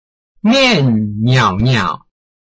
拼音查詢：【饒平腔】ngiau ~請點選不同聲調拼音聽聽看!(例字漢字部分屬參考性質)